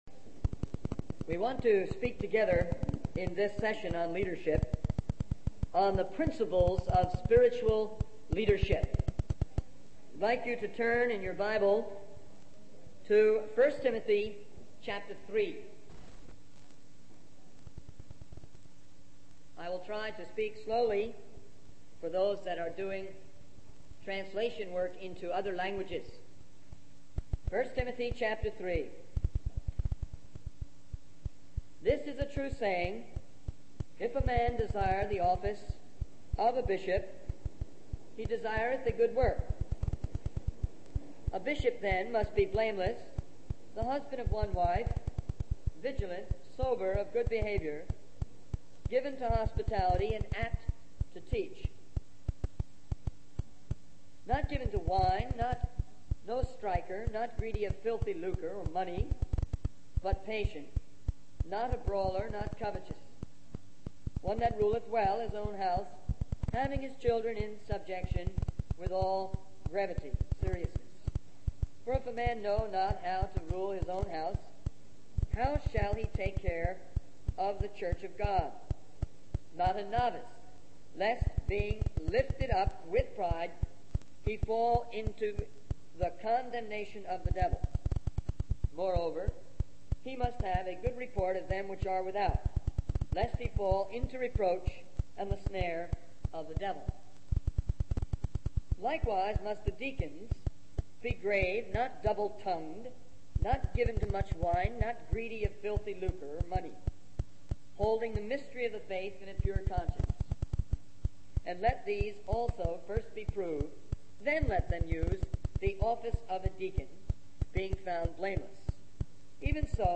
In this sermon, the speaker emphasizes the importance of spiritual leadership and the responsibility that comes with it. He highlights the need for leaders to be men of prayer and to have a vision for their work.